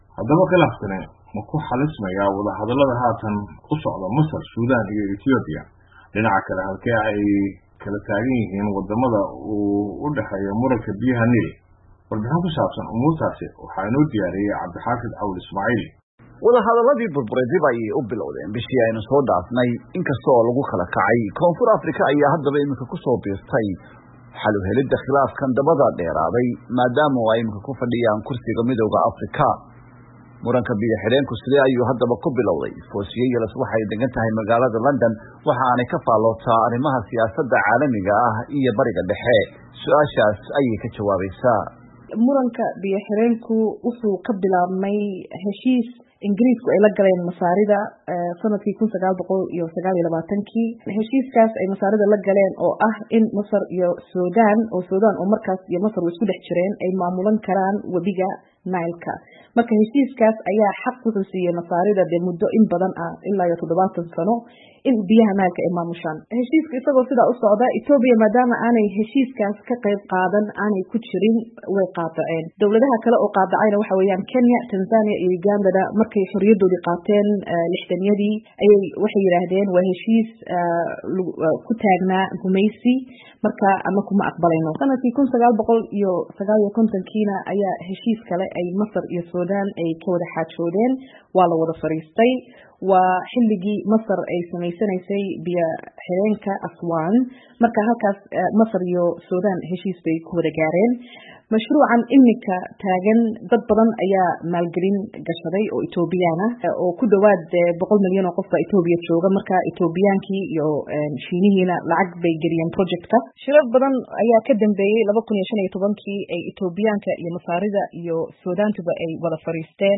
Warbixin: Wadahadallada biya-xireenka wabiga Nile